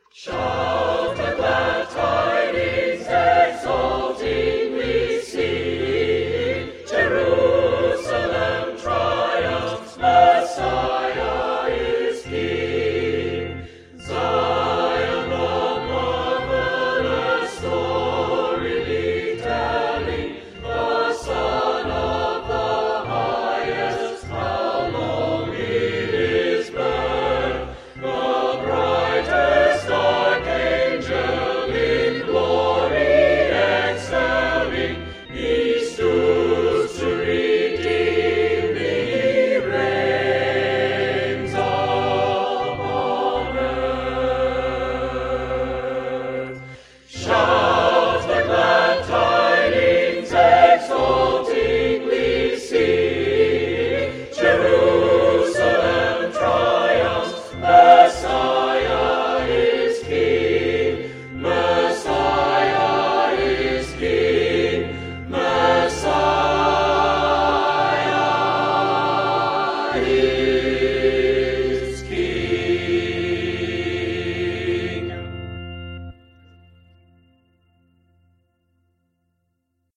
Choral music for